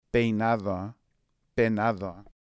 El contraste entre [e] y [eI] en español.